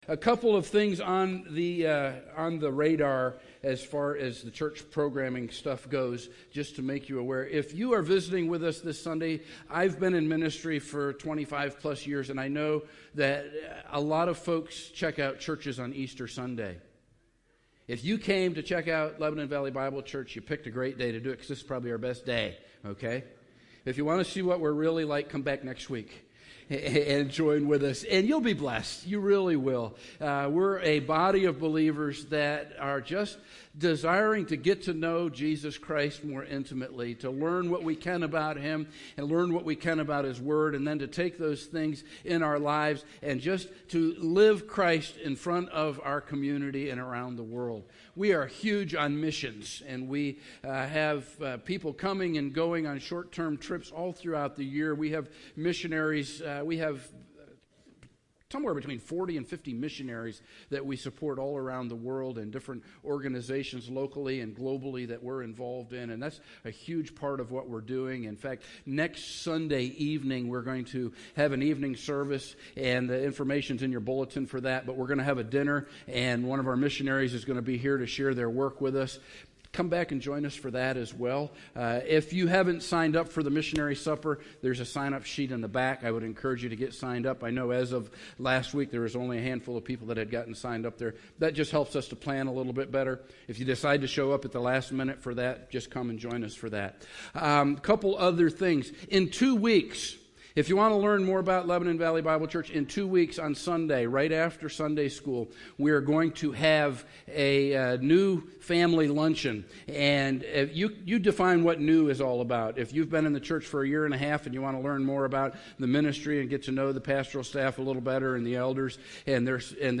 Holiday Sermon